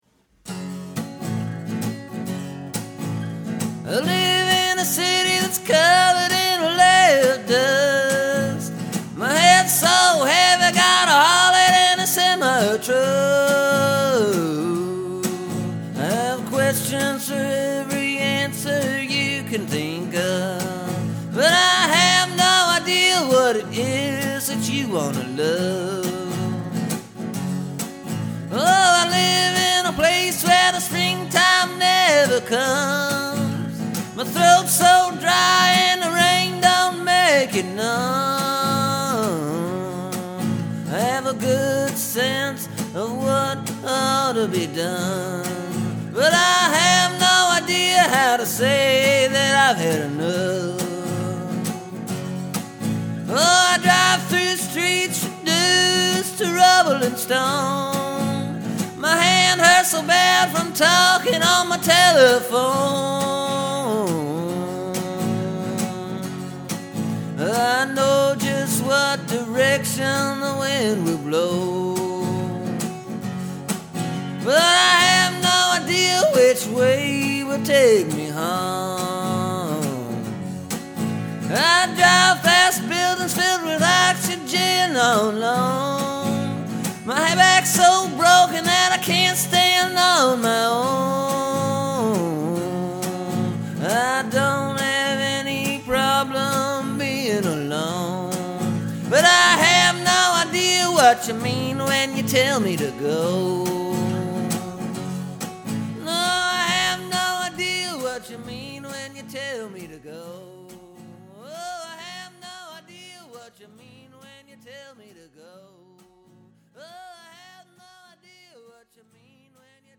Just maybe the guitar part and the melody and singing a little.
The guitar part is loosely based on my song “Lawd Almighty.”